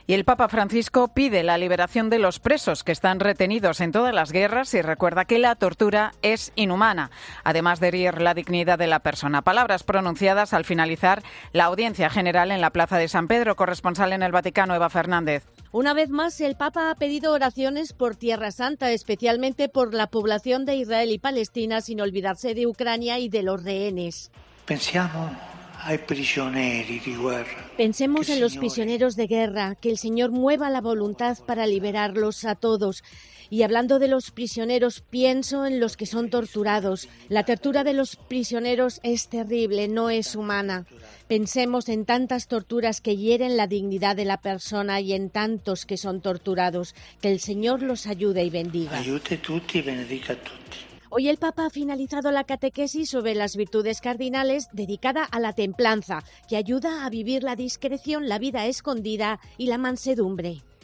En la audiencia general, el Pontífice reza para que el Señor "mueva voluntades para liberar a todos" los que son sometidos a torturas que "hieren la dignidad de las personas"